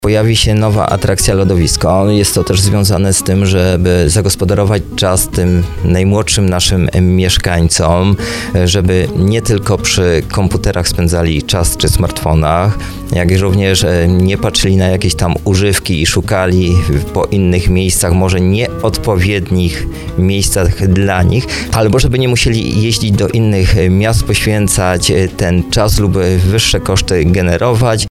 Na antenie RDN Małopolska zwracał uwagę, że to dobry sposób na aktywne spędzenie wolnego czasu, tym bardziej że wcześniej miłośnicy jazdy na łyżwach musieli dojeżdżać do oddalonej o kilkadziesiąt kilometrów Dębicy.